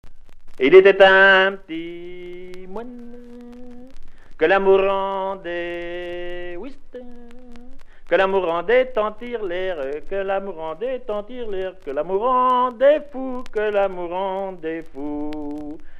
Il était un p'tit moine Cette Pièce musicale inédite a pour titre "Il était un p'tit moine".
voix seule